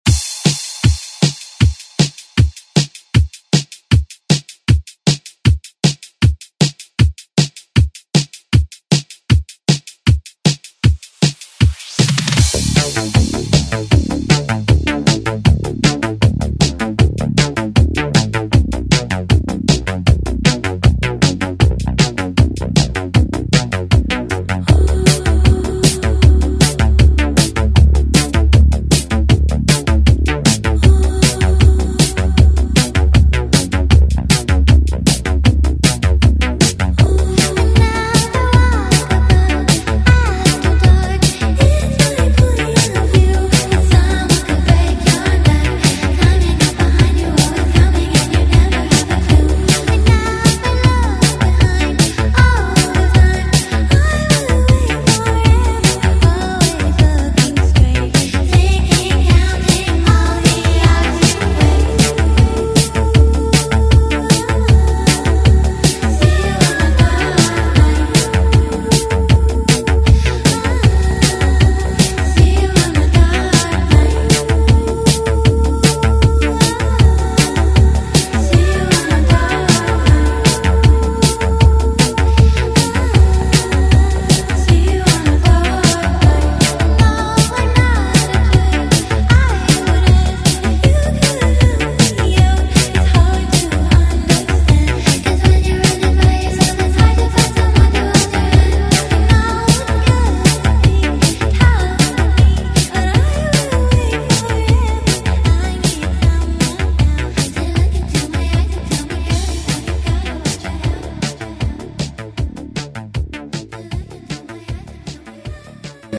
Genre: 80's
Clean BPM: 120 Time